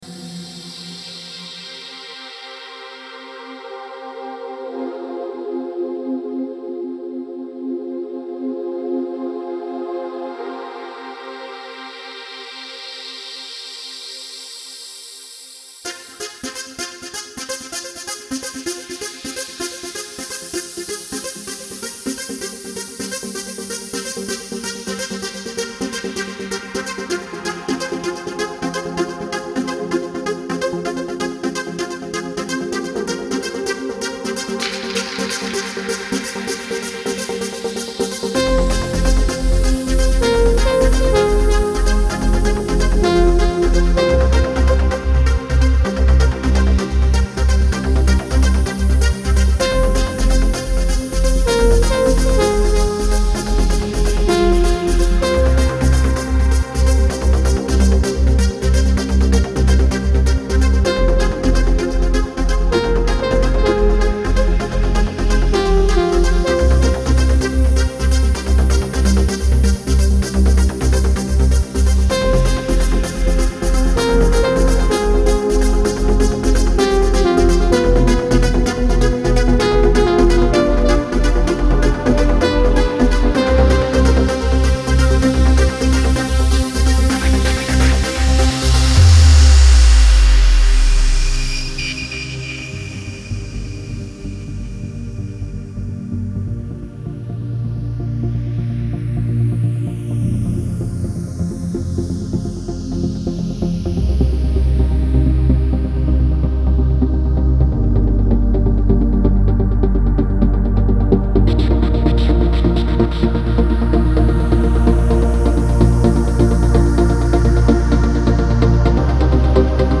Genre : Électronique, Techno